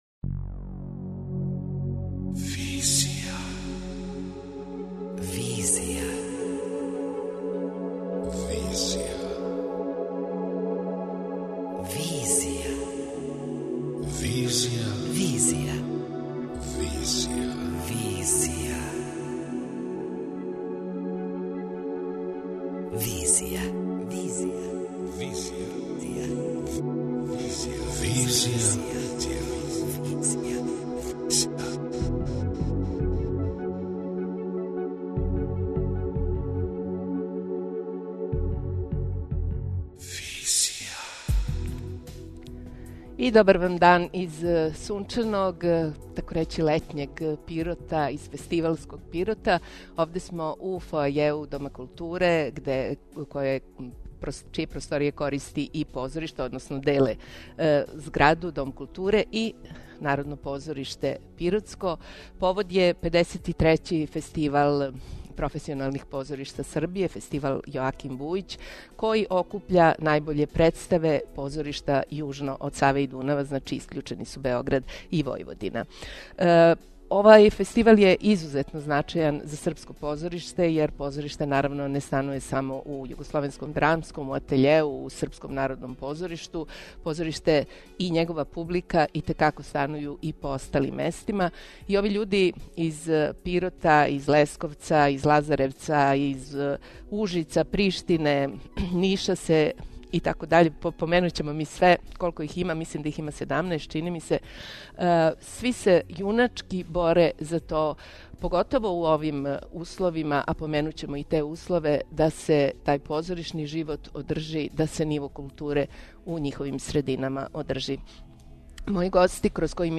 Специјално издање емисија Визија и Ars, Аrtifex данас се емитује из Народног позоришта у Пироту.